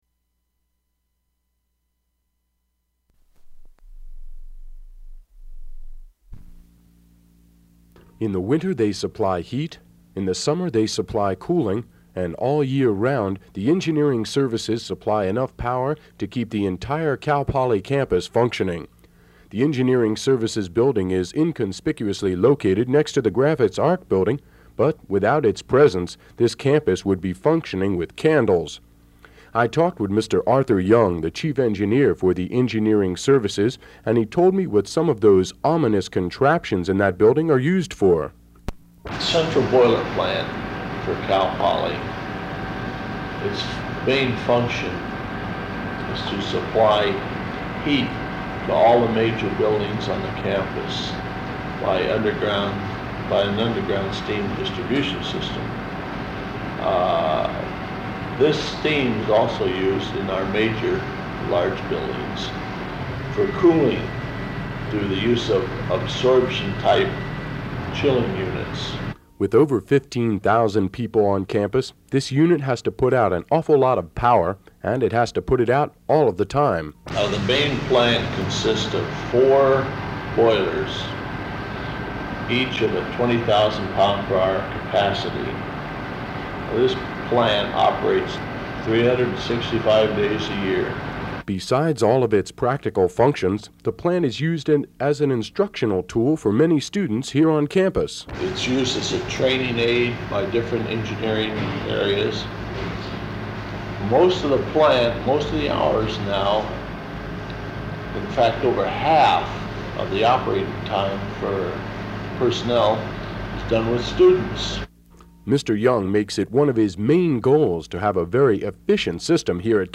Compilation of news features recorded for KCPR's Newsday